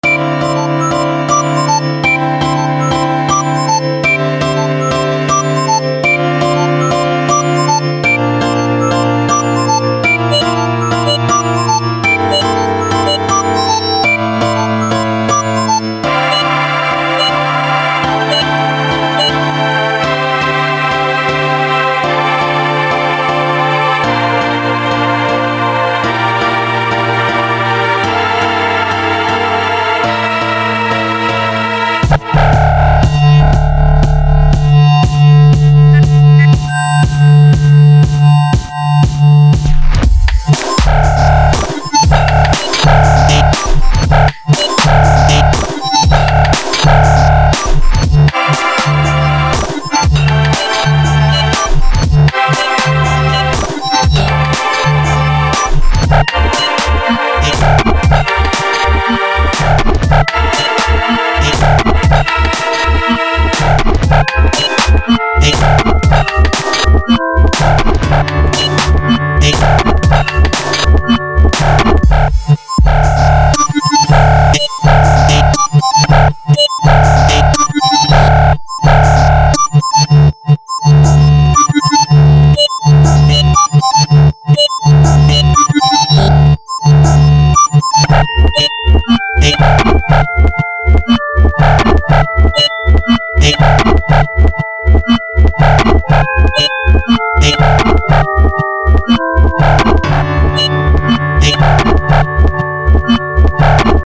was experimenting with creating modulation stuff using vopm and cheating with volume levels earlier today. figured id use that experiment for ohc basis.